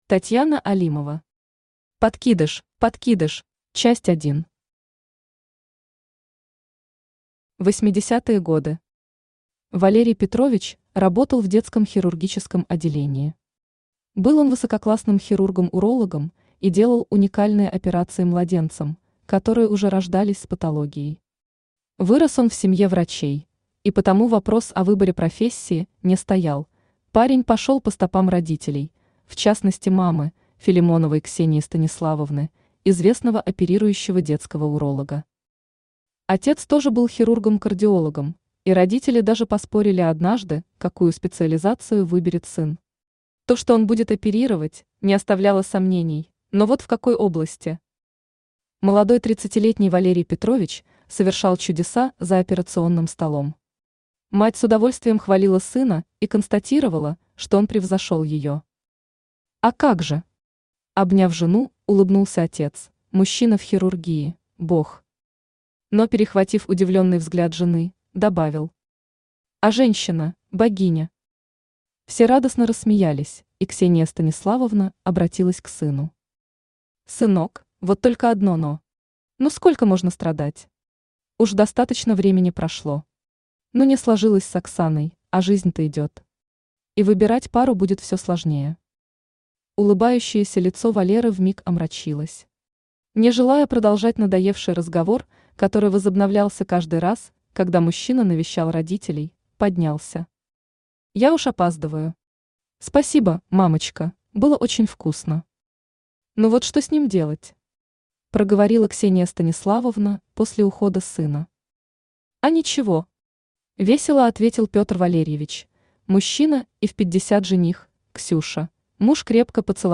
Аудиокнига Подкидыш | Библиотека аудиокниг
Aудиокнига Подкидыш Автор Татьяна Алимова Читает аудиокнигу Авточтец ЛитРес.